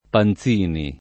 [ pan Z& ni ]